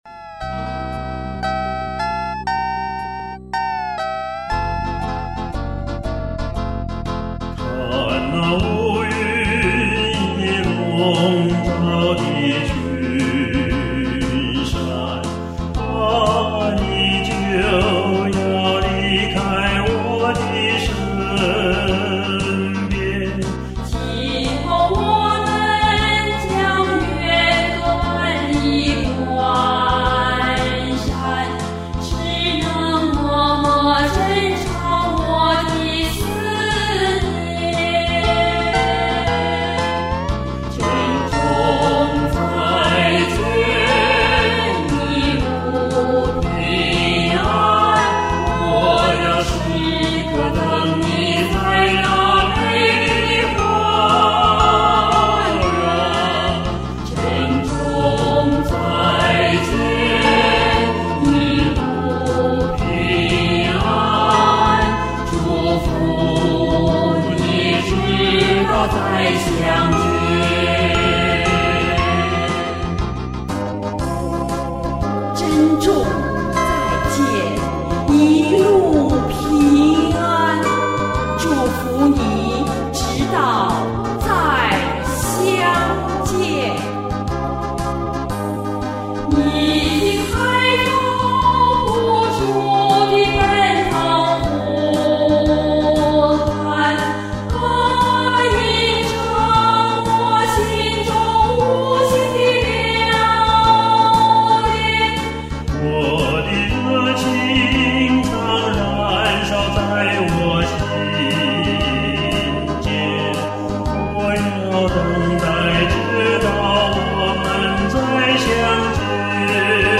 夏威夷民歌